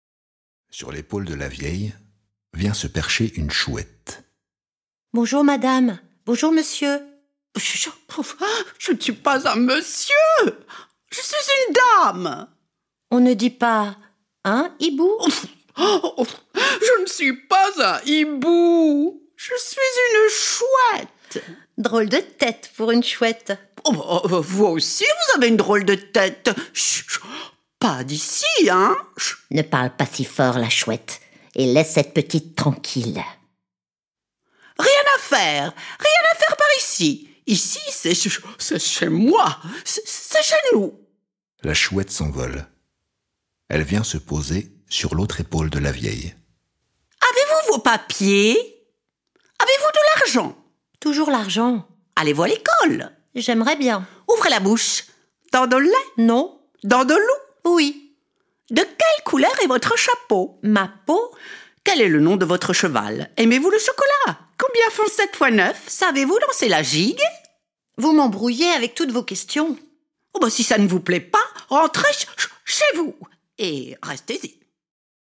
où fourmillent et déferlent les ombres, les bêtes et les bruits, au son des guitares, d’un accordéon et d’un hélicon.